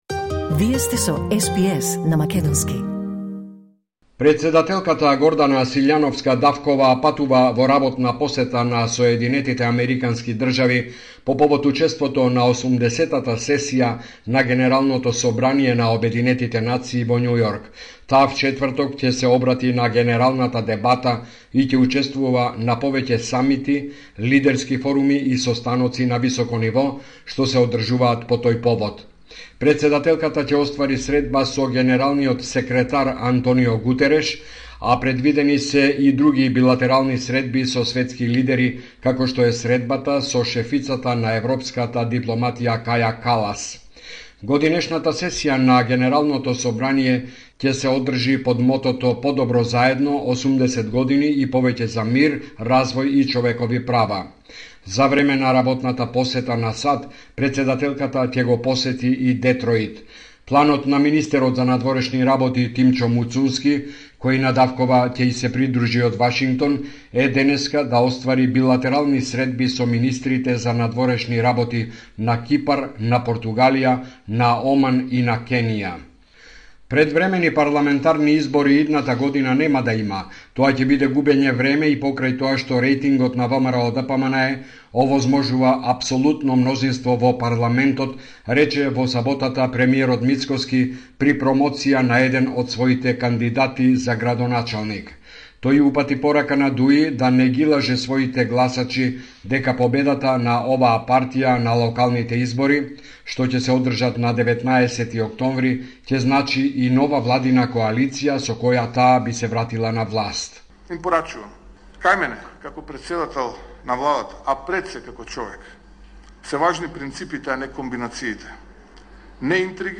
Homeland Report in Macedonian 22 September 2025